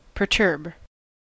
[pɚˈtɚb]) in General American pronunciation.
En-us-perturb.ogg.mp3